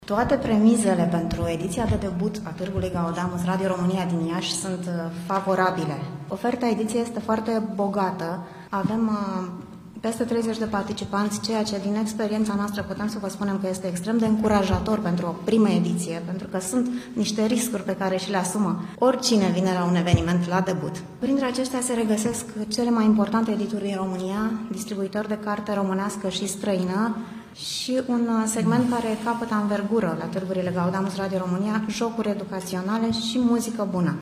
într-o conferinţă de presă defăşurată la sediul studioului nostru de radio